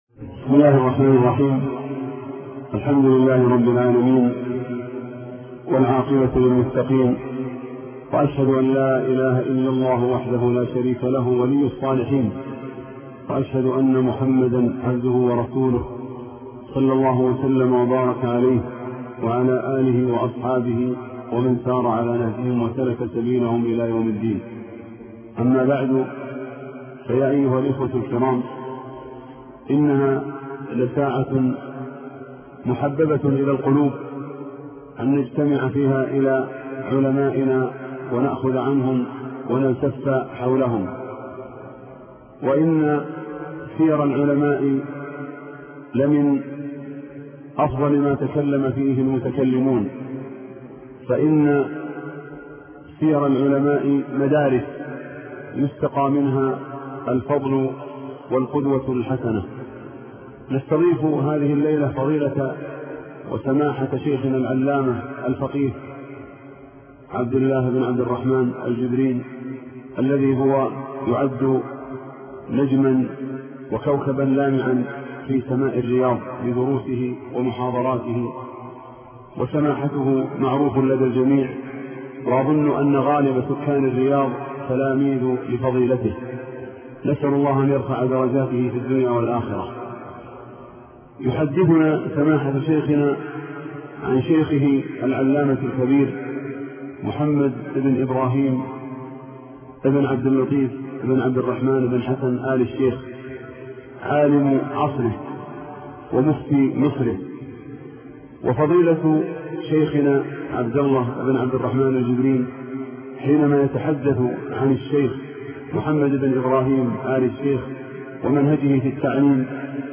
أرشيف الإسلام - أرشيف صوتي لدروس وخطب ومحاضرات الشيخ عبد الله بن عبد الرحمن بن جبرين